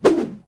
footswing6.ogg